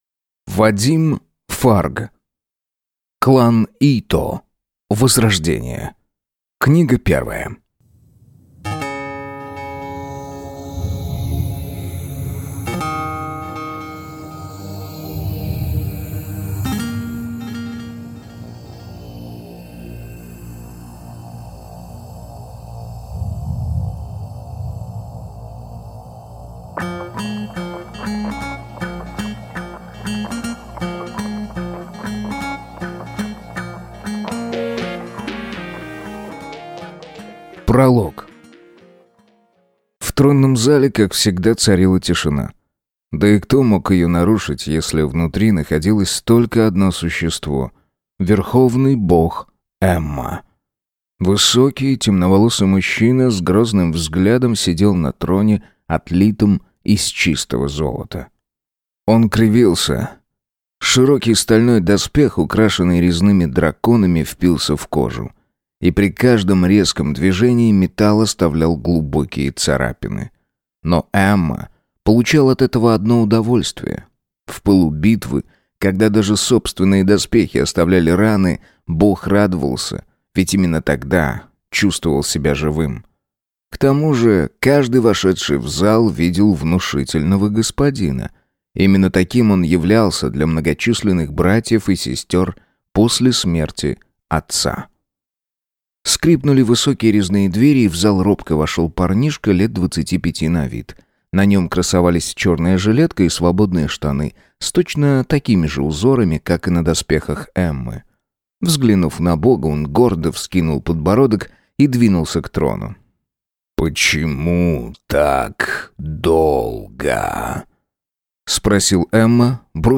Аудиокнига Клан Ито. Возрождение | Библиотека аудиокниг
Прослушать и бесплатно скачать фрагмент аудиокниги